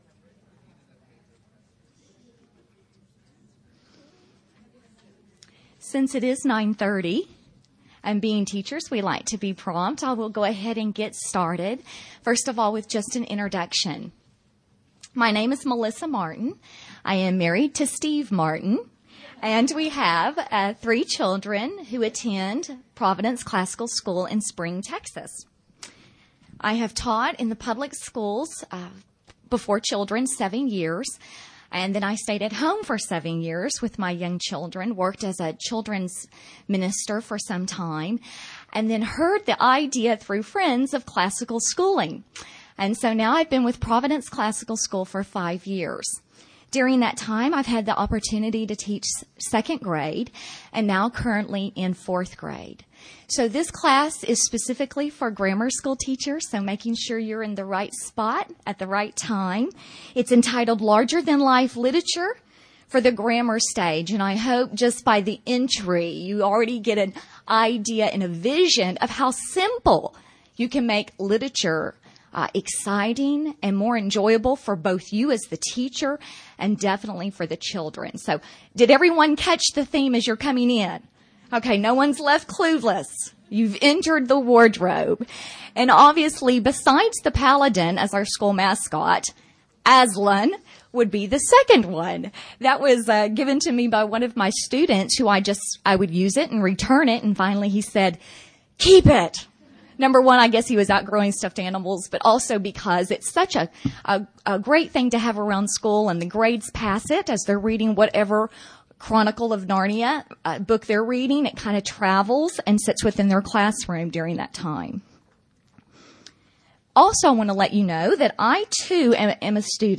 2008 Workshop Talk | 1:04:37 | K-6, Literature
Jan 31, 2019 | Conference Talks, K-6, Library, Literature, Media_Audio, Workshop Talk | 0 comments